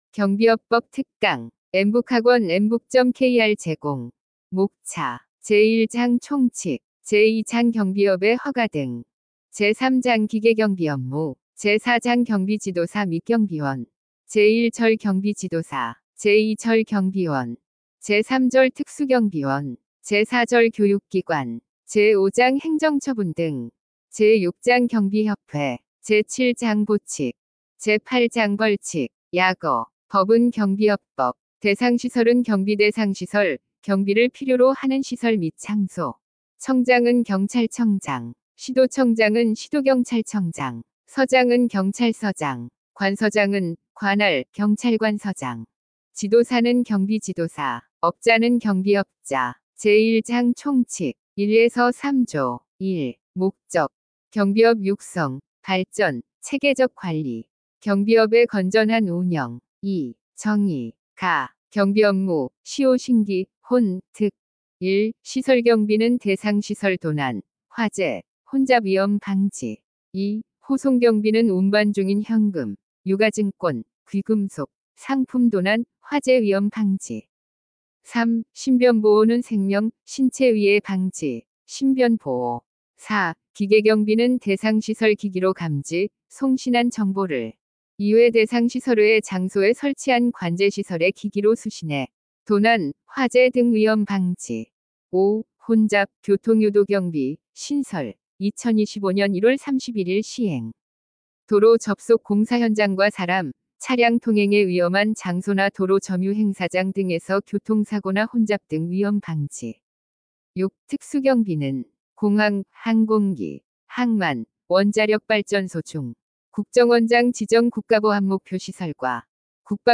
엠북학원은 우리나라 최초의 인공지능이 강의하는 사이버학원이며, 2025년 4월 28일 개원하였습니다.
경비업법-특강-샘플.mp3